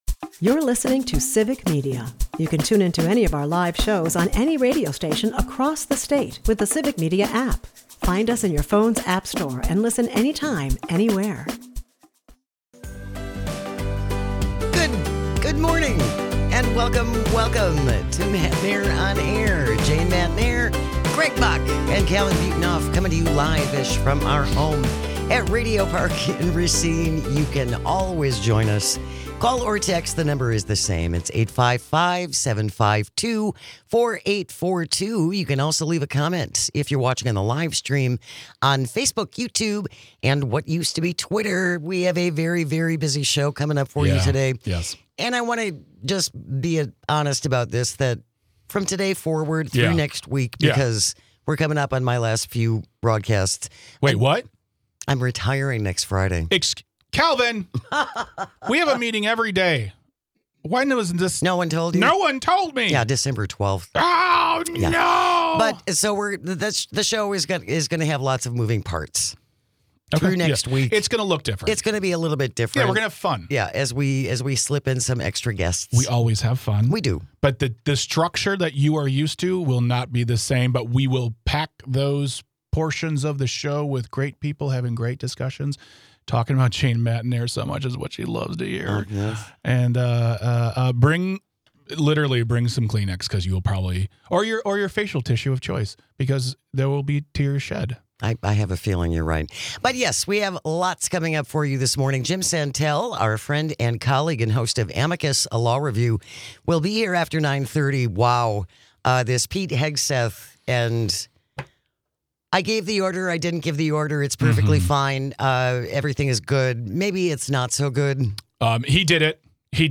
Guests: Jim Santelle